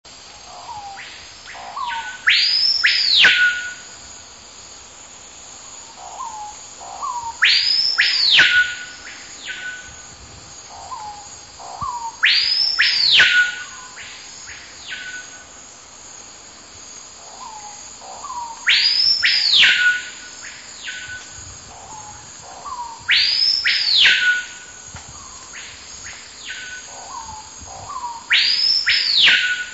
Esta é a vocalização do
cricrió-seringueiro, Lipaugus vociferans, cujo nome comum já indica o seu local de ocorrência que é na Amazônia.